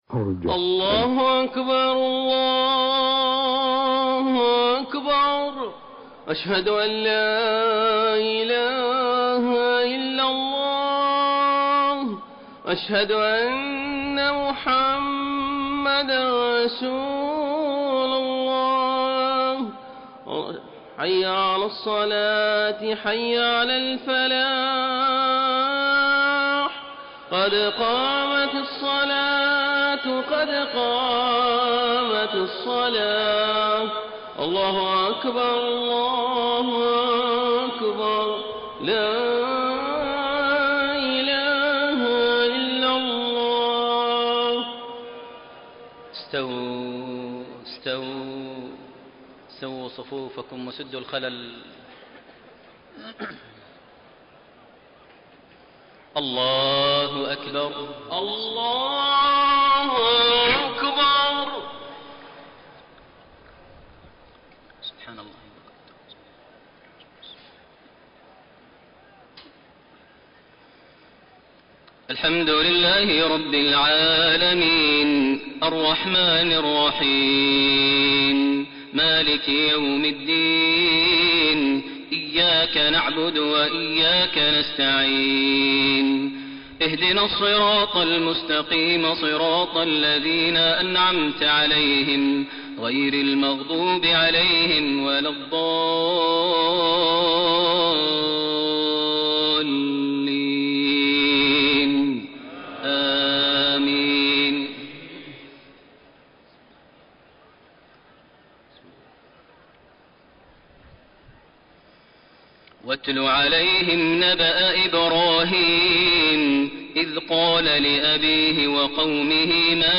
صلاة العشاء 19 ذو الحجة 1432هـ من سورة الشعراء 69-104 > 1432 هـ > الفروض - تلاوات ماهر المعيقلي